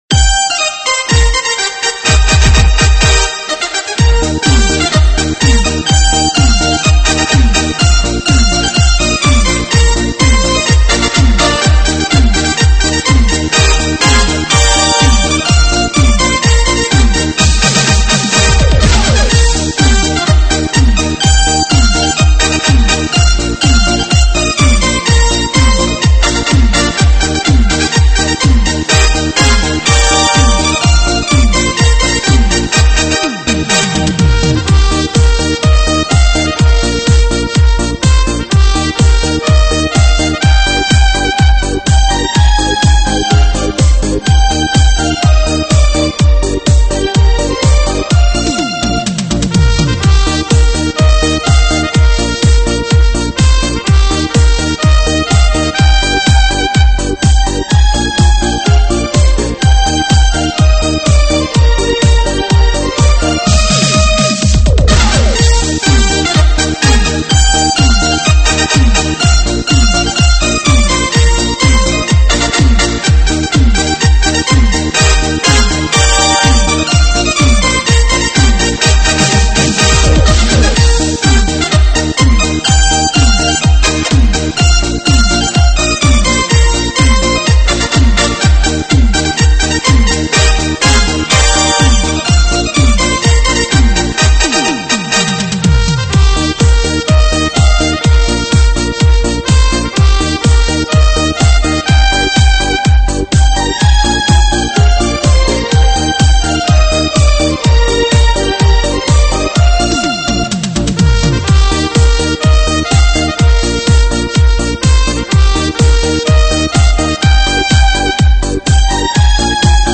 舞曲类别：慢摇舞曲